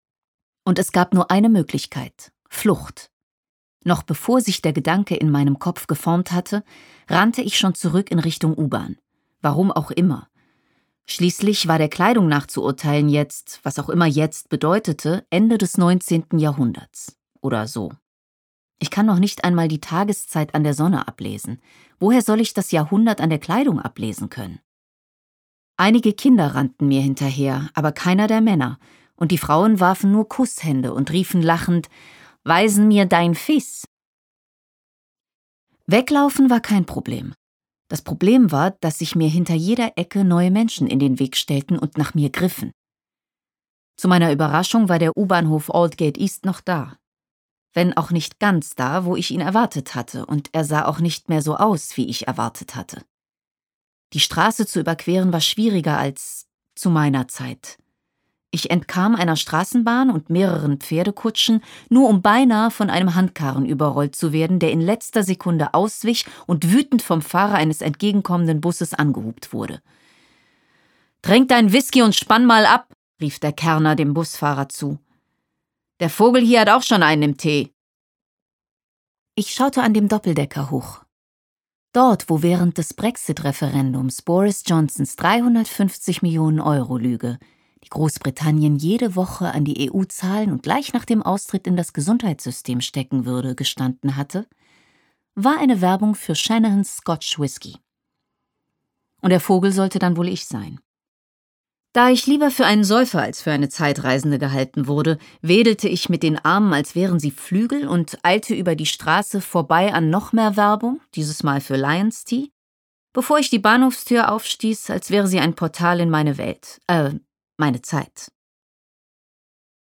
Melika Foroutans Lesung führt uns souverän und mit hintergründigem Witz durch Mithu Sanyals wildes Universum.